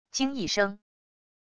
惊异声wav音频